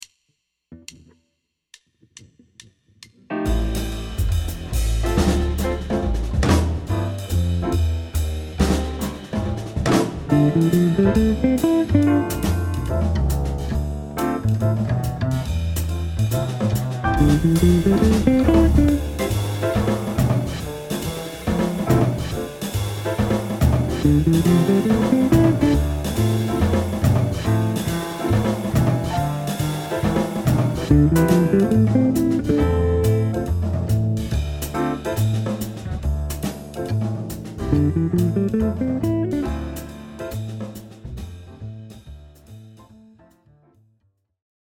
La note cible est approchée par la note diatonique inférieure suivie de la note diatonique supérieure.
Phrase 01#min – sur accord mineur
Préparation de la note finale (note D) qui est ciblée par sa note diatonique précédente dans le mode (note C) et sa note diatonique suivante (note E) avant de l’atteindre